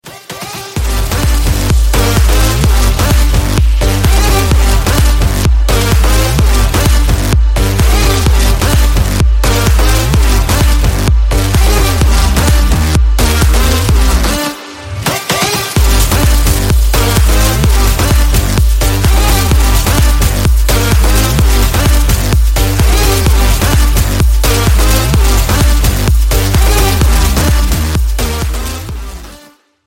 Рингтоны Электроника